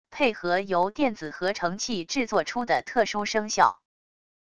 配合由电子合成器制作出的特殊声效wav音频